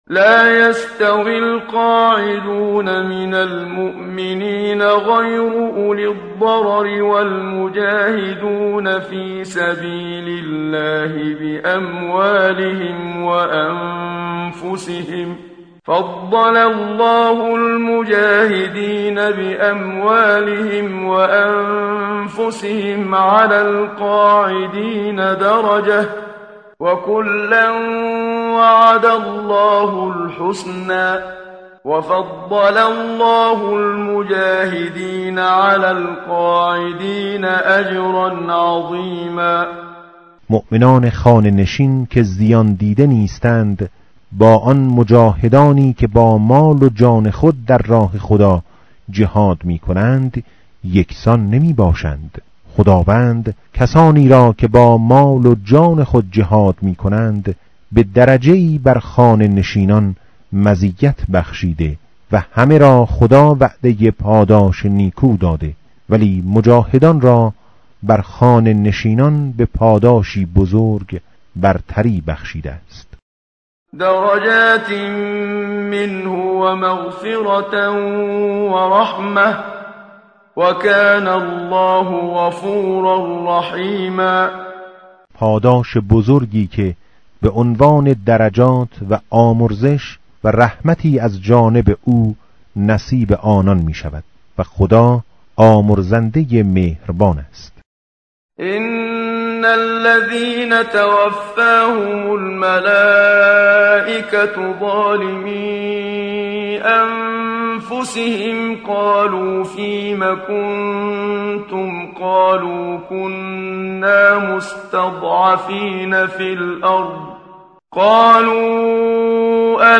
tartil_menshavi va tarjome_Page_094.mp3